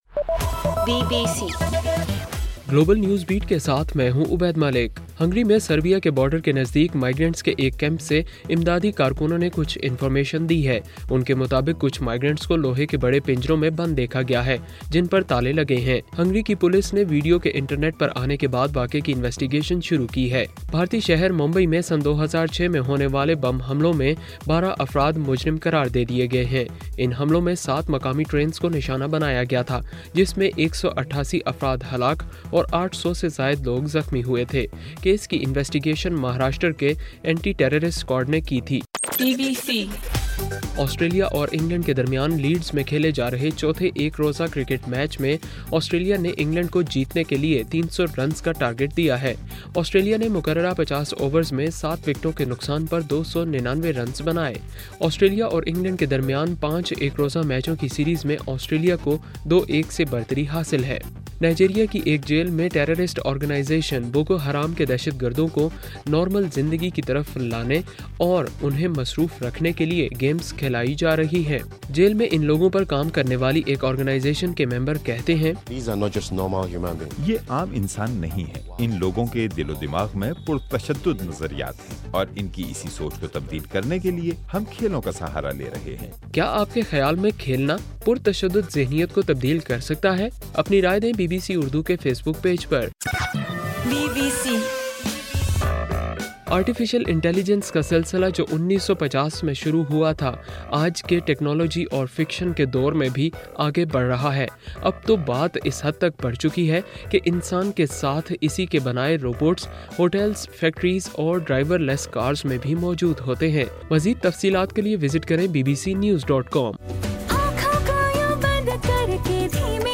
ستمبر 11: رات 8 بجے کا گلوبل نیوز بیٹ بُلیٹن